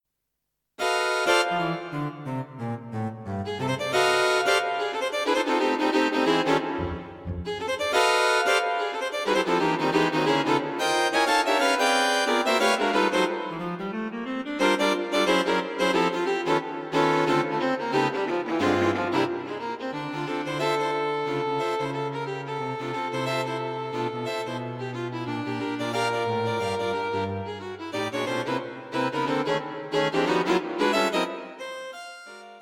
A fast jazz piece.